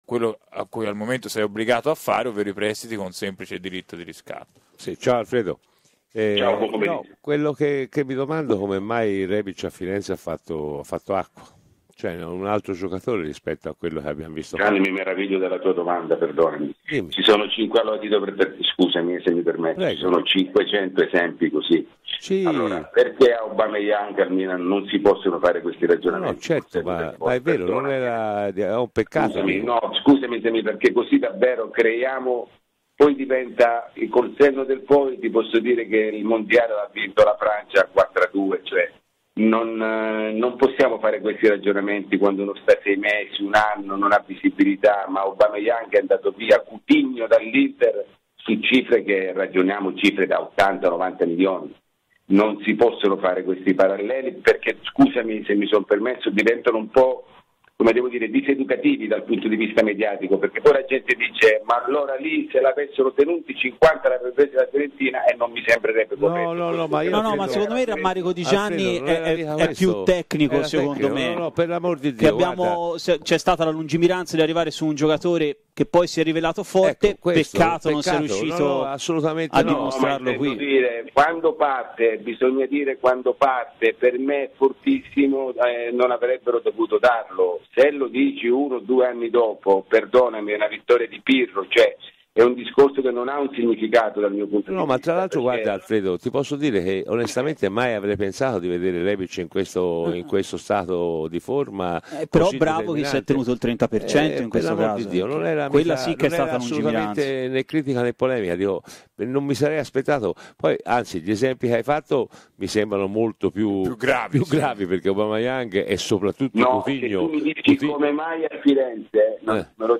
Questa la zuffa radiofonica: